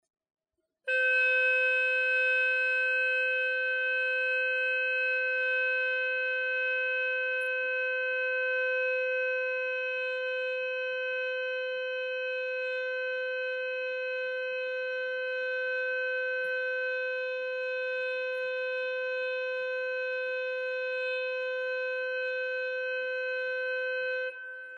附两个长音的示范：